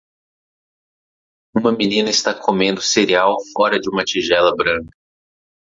Pronúnciase como (IPA)
/se.ɾeˈaw/